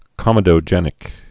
(kŏmĭ-dō-jĕnĭk)